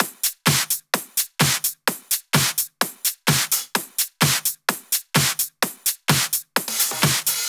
VDE 128BPM Silver Drums 2.wav